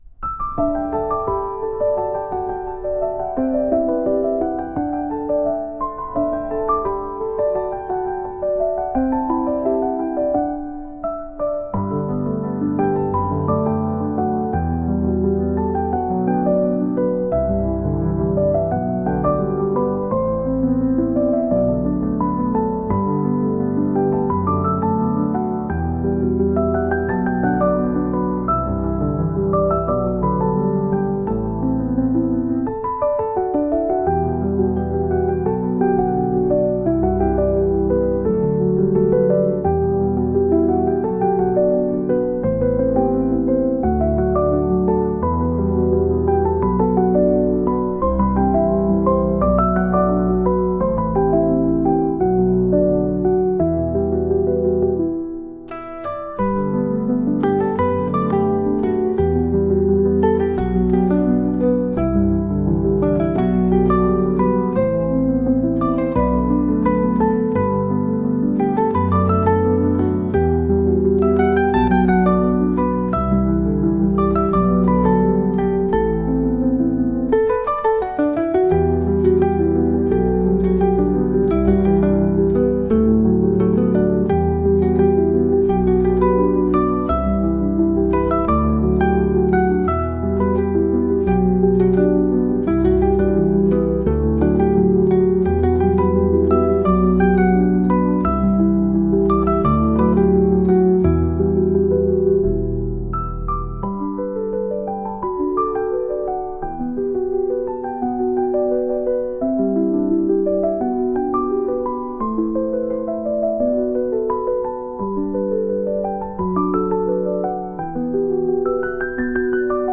凛とした空気を漂わせるようなピアノ曲です。